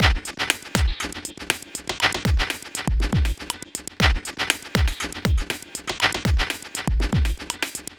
Doing Stuff (Beats) 120BPM.wav